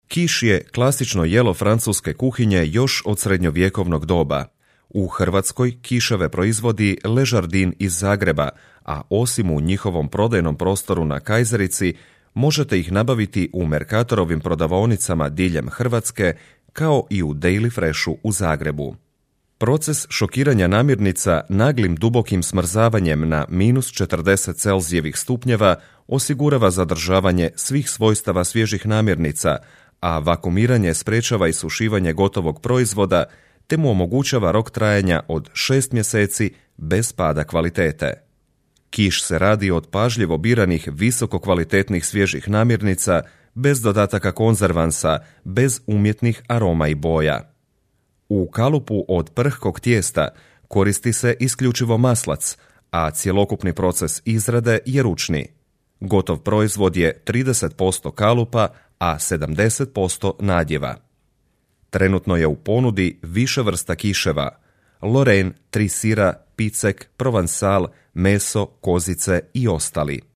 Experienced voice-over talent for Croatian, Bosnian and Serbian market.
Kein Dialekt
Sprechprobe: eLearning (Muttersprache):